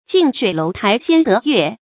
近水楼台先得月 jìn shuǐ lóu tái xiān dé yuè
近水楼台先得月发音